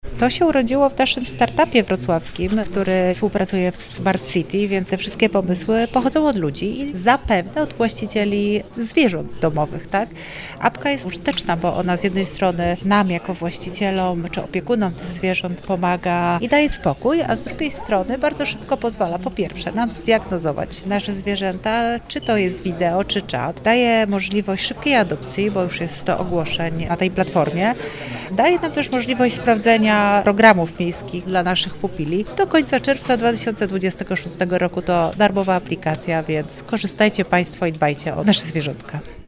– Sygnał na udostępnienie takiej funkcji otrzymaliśmy oddolnie, w ramach projektu „Wrocław City Lab”, a sama aplikacja jest bardzo użyteczna – mówi Renata Granowska, Wiceprezydent Wrocławia.